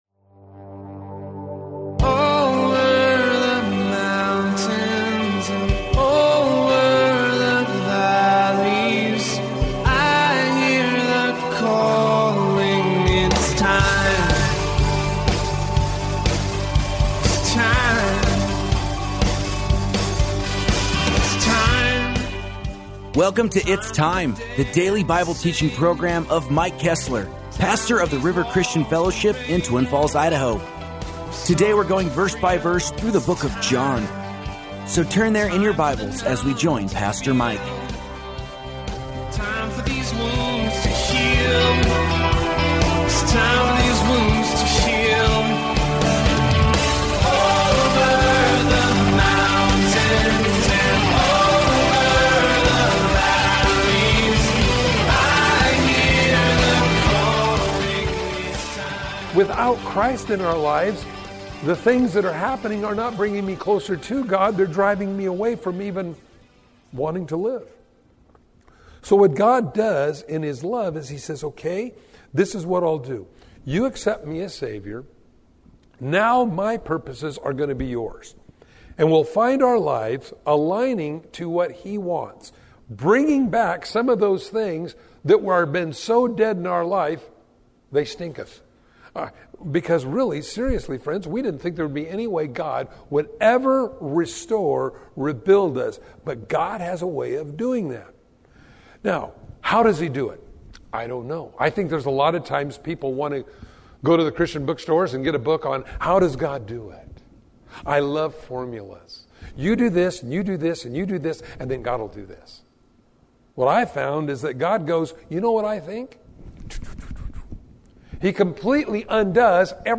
Bible-teaching radio ministry